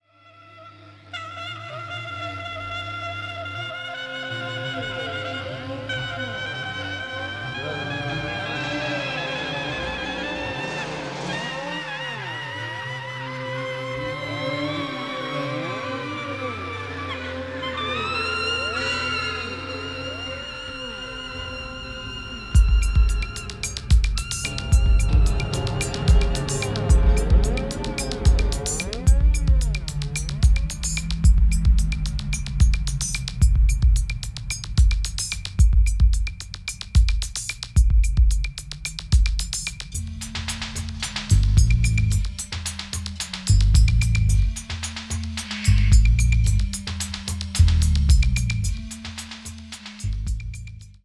Recorded at Amuse Studio and Metal Box Studio, Tokyo, Japan
Mixed at the Hit Factory, New York City
drums, drum machine, percussion
kora, talking drum, vocals
trumpet, synthesizer
Japanese flute